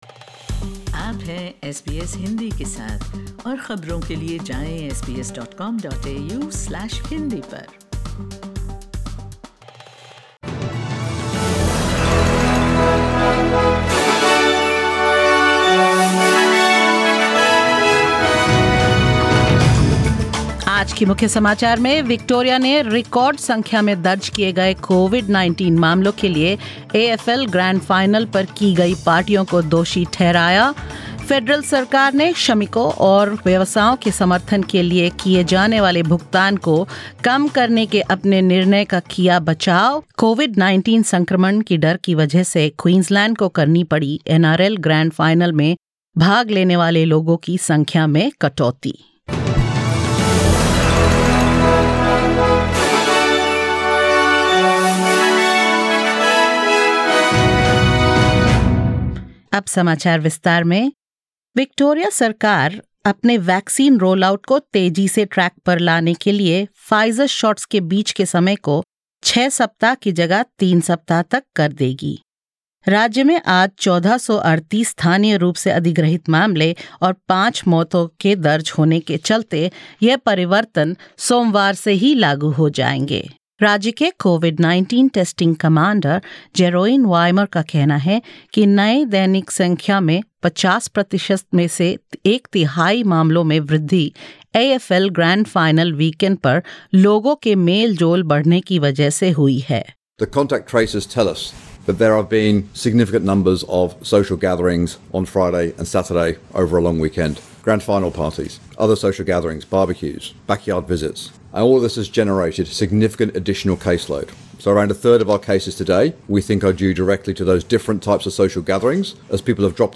In this latest SBS Hindi News bulletin of Australia and India: : The federal government justifies its decision to cut government support for workers and businesses; Victoria blames illegal long weekend get-togethers for a record number of new COVID-19 cases; Queensland’s latest Coronavirus scare forces NRL grand final crowd numbers to be slashed and more.